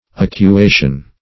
Acuation \Ac`u*a"tion\, n. Act of sharpening.